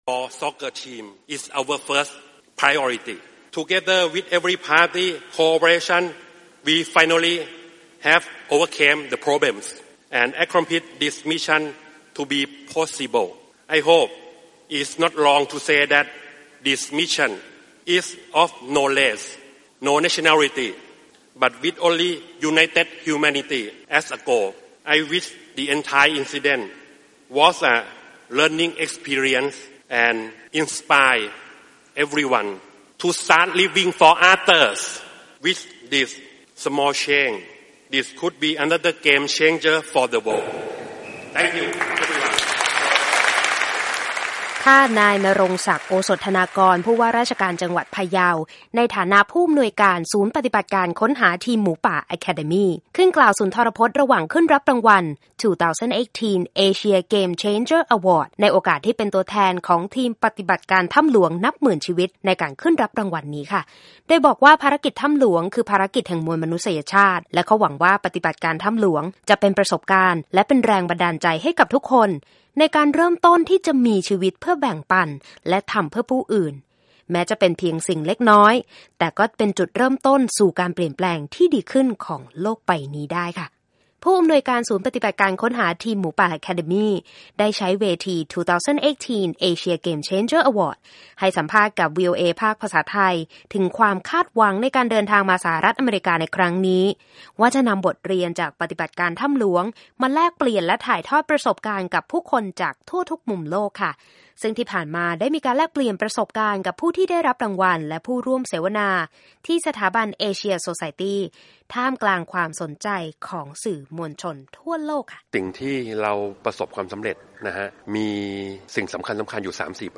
รายงานจากนครนิวยอร์ก